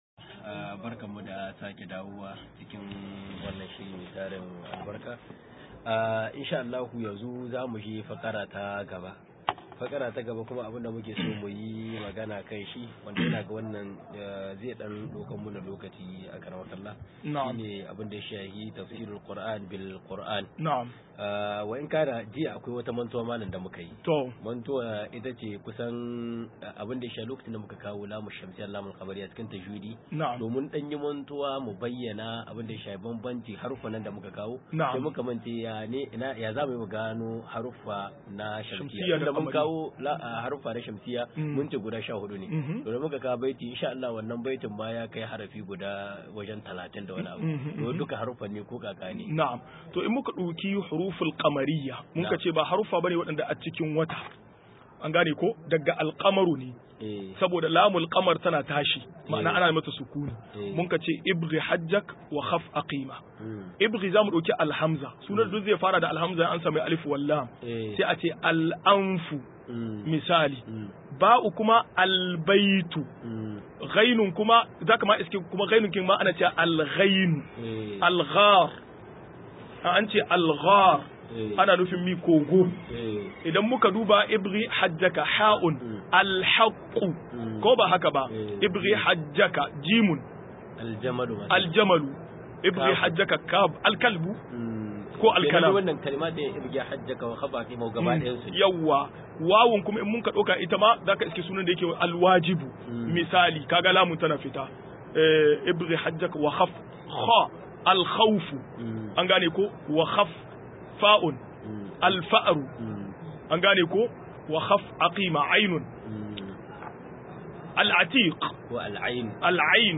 172-Ka i dodin Fassara Alkur Ani da Alkur a ni 1 - MUHADARA